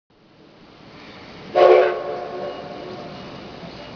〜車両の音〜
C57汽笛
鉄道博物館で保存されている135号機で何度か汽笛を鳴らしていたので偶々録ったのですが、録れたのはごく短い汽笛だけでした…